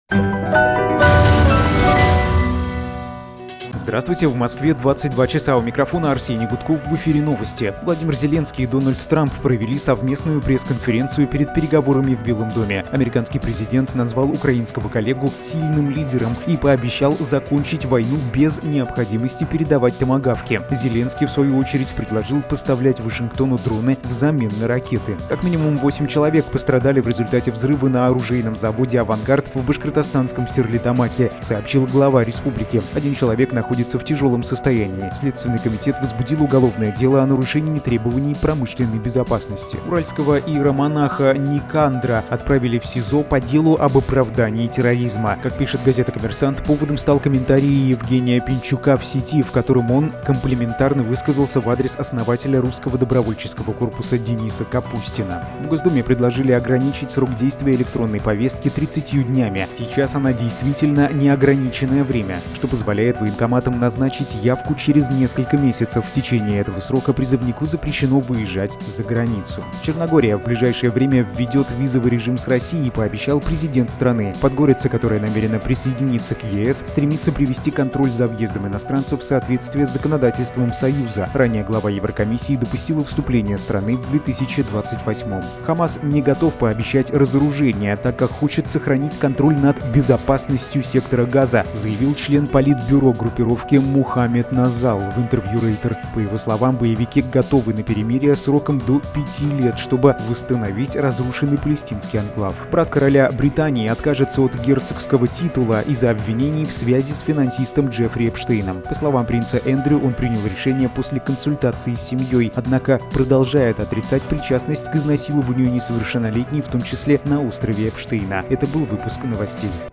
Новости 22:00